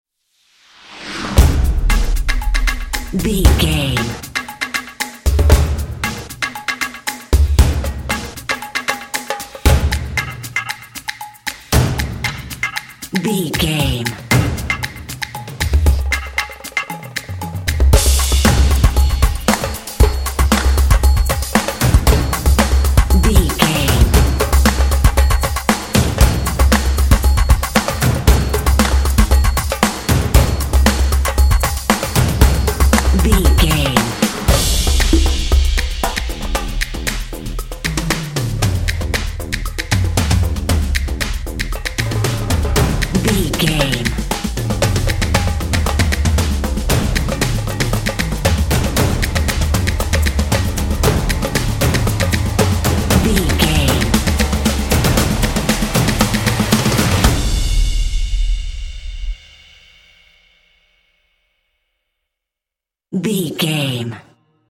Epic / Action
Atonal
groovy
intense
driving
energetic
drumline